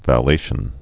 (vă-lāshən)